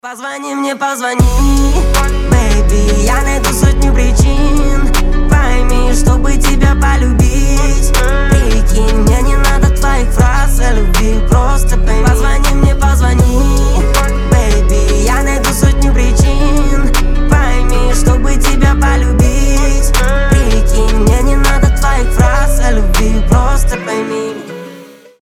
• Качество: 320, Stereo
RnB